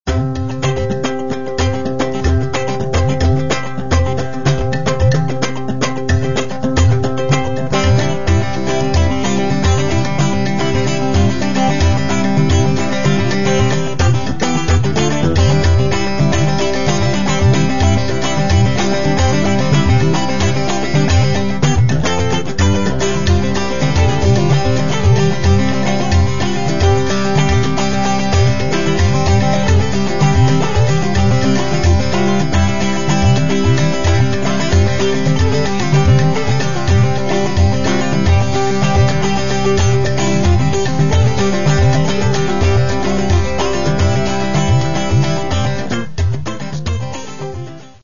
Каталог -> Джаз и около -> Этно-джаз и фольк
электрогитары, вокал, перкуссия